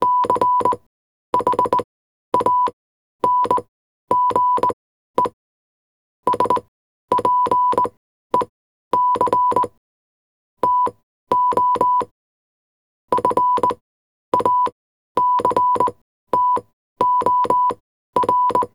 이거 모스부호다.